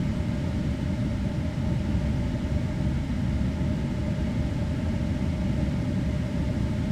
background_air_vent_fan_loop_02.wav